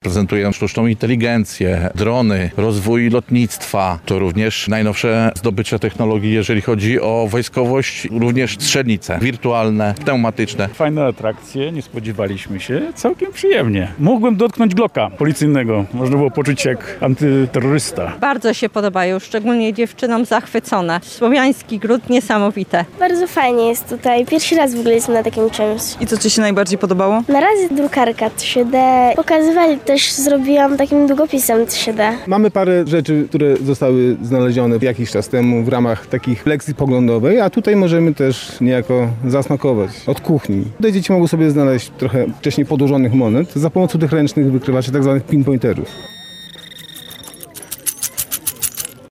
Mogłem dotknąć glocka policyjnego i poczuć się jak antyterrorysta – mówi jeden z odwiedzających piknik.
Jest tu bardzo fajnie – dodają inni mieszkańcy Lublina.